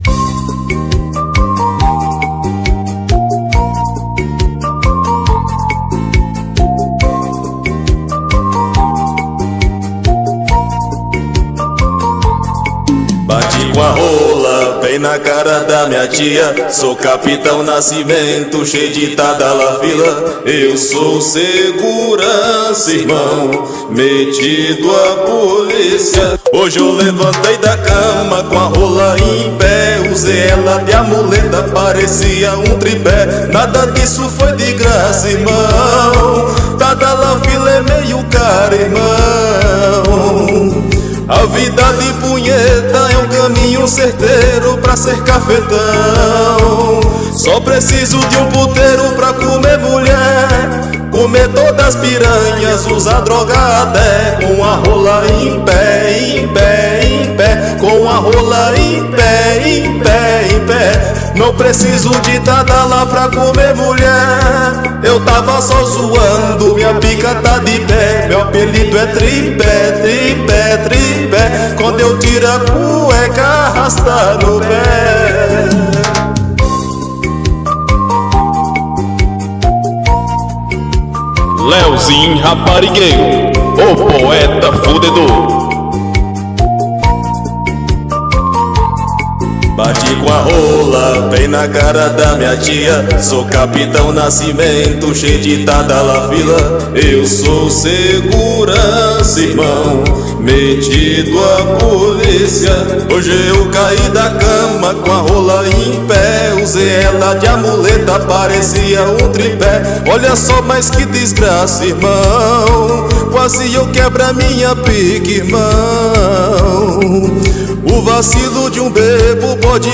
2025-01-03 18:06:51 Gênero: Sertanejo Views